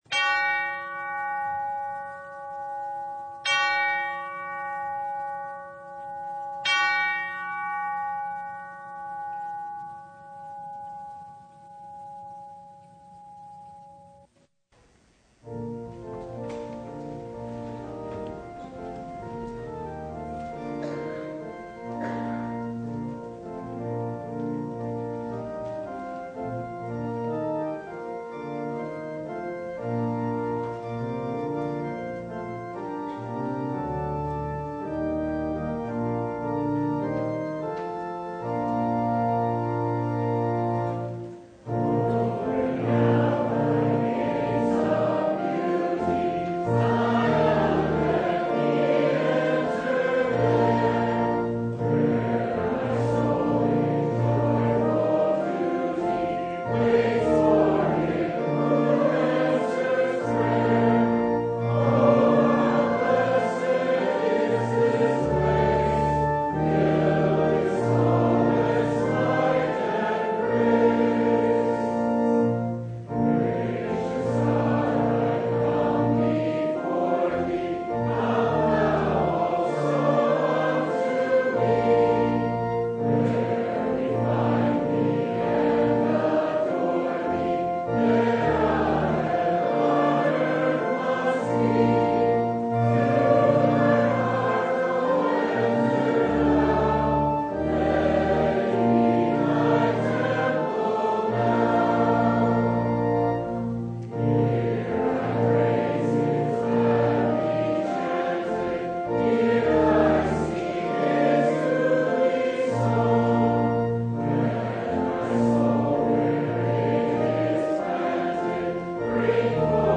Mark 9:30-37 Service Type: Sunday Who is the greatest?
Download Files Notes Bulletin Topics: Full Service « Help My Unbelief Who Is the Greatest?